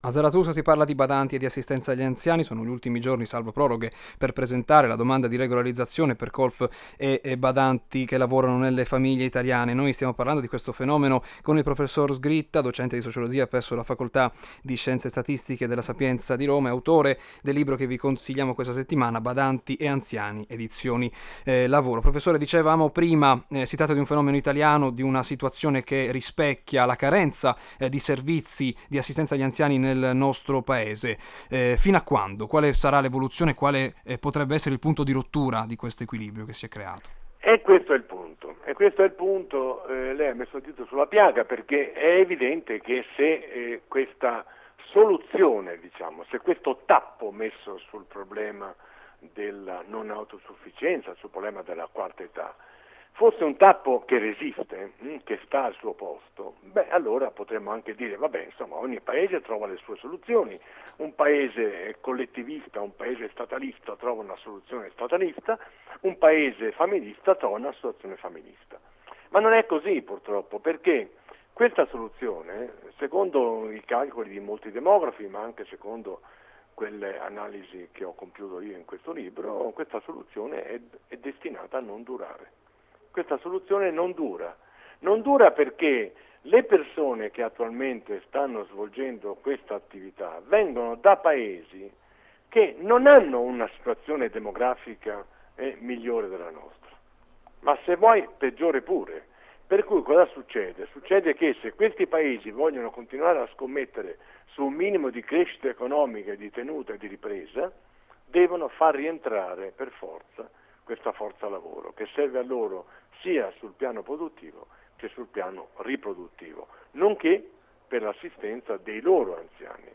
Ascolta la puntata di Zarathustra andata in onda sabato 26 settembre, alle 18,05, su Radio Italia anni '60 - Emilia Romagna.